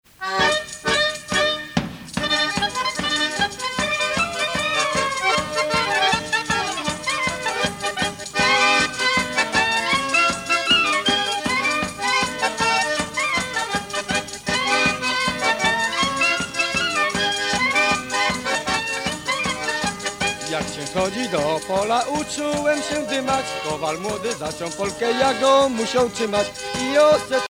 danse : polka
Enquête Lacito-CNRS
Pièce musicale inédite